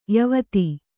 ♪ yuvati